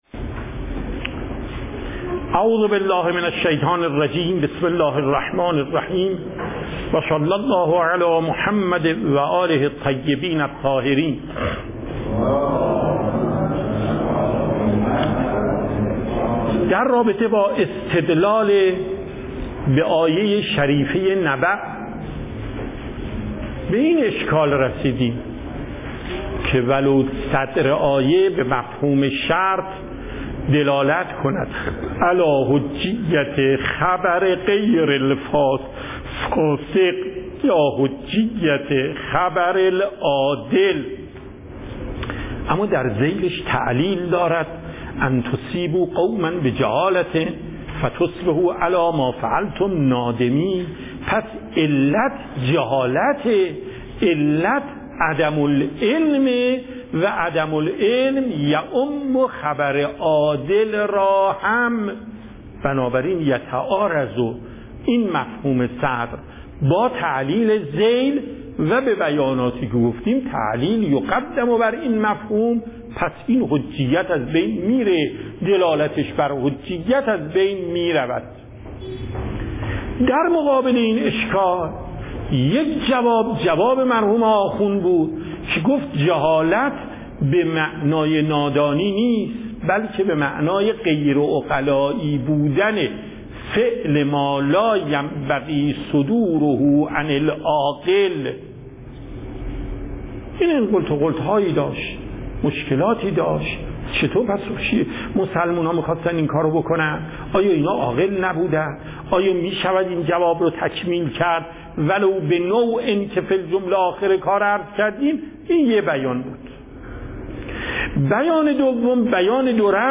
صوت درس
درس اصول آیت الله محقق داماد